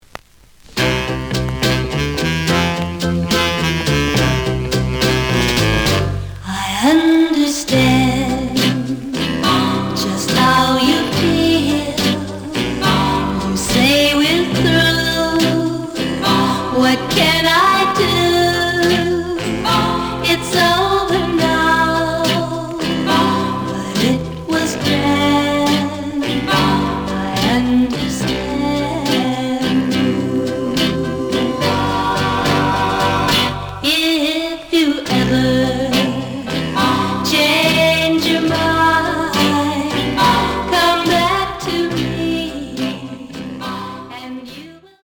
The audio sample is recorded from the actual item.
●Genre: Rock / Pop
Slight damage on both side labels. Plays good.)